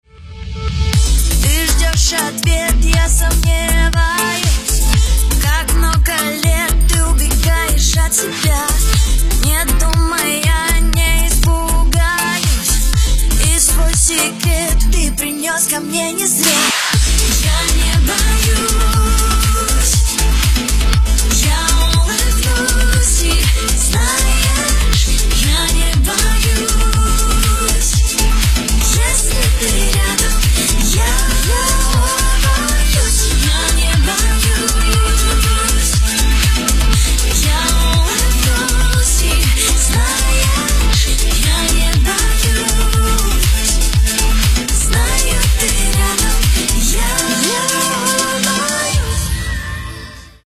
• Качество: 320, Stereo
поп
женский вокал
dance